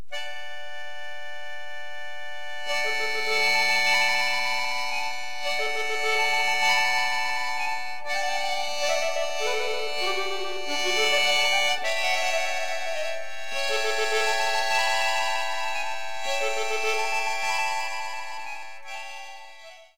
She is an excellent pianist, accordionist, performer.